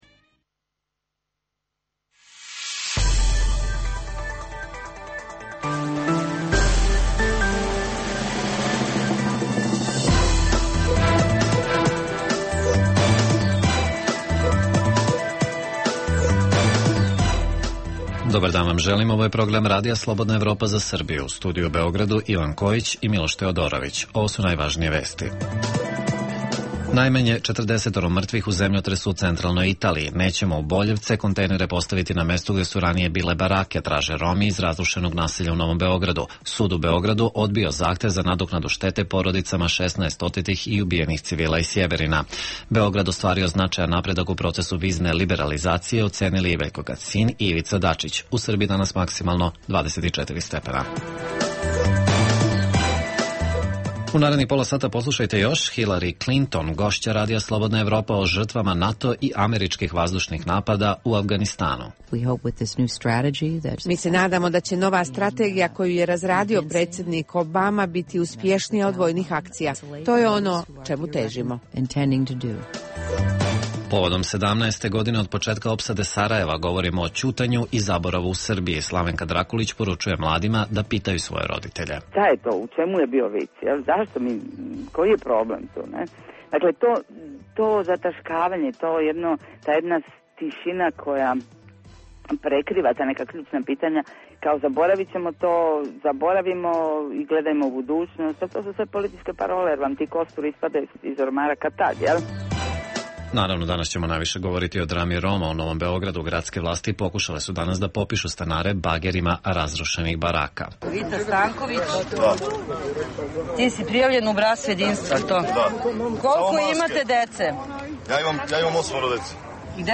Takođe, poslušajte i deo intervjua koji je našem Radiju dala Hilari Klinton, kao i komentare i sećanja povodom 17. godine od početka opsade Sarajeva.